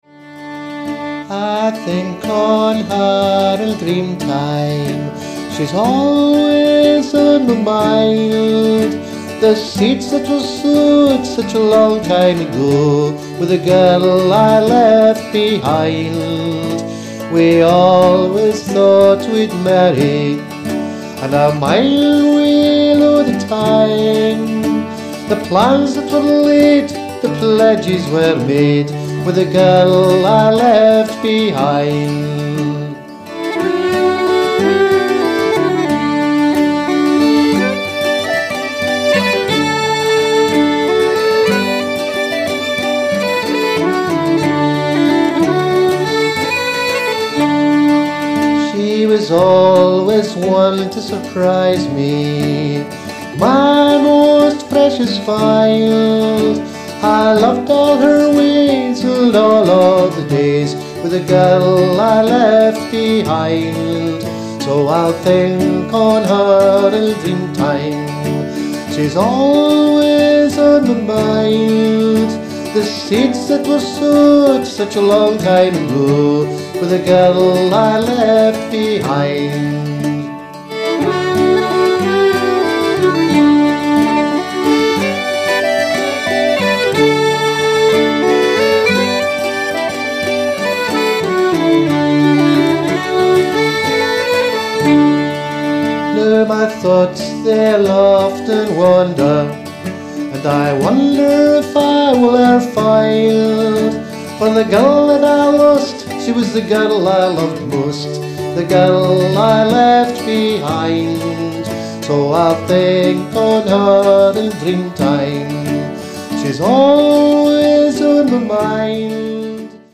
uilleann pipes and whistle
button box
fiddle
guitar and octave mandolin